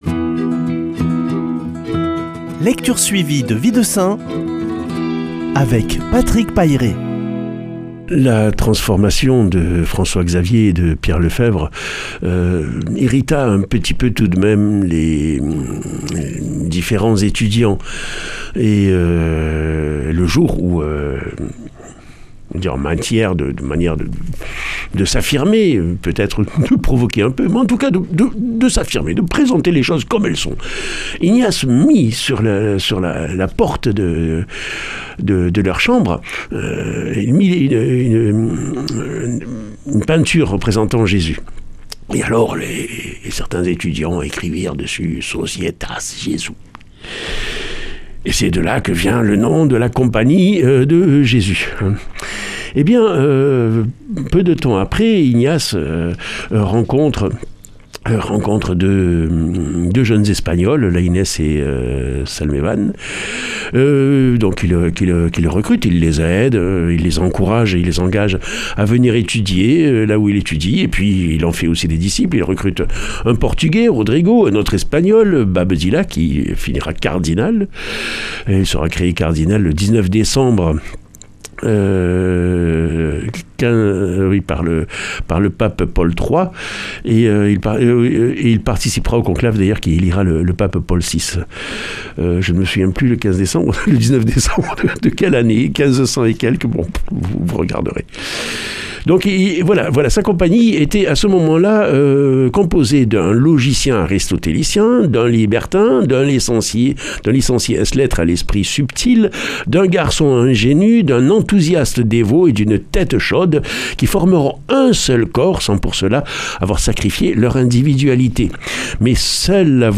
Lecture suivie de la vie des saints - Radio Présence
mercredi 13 mai 2020 Récit de vie de saints Durée 2 min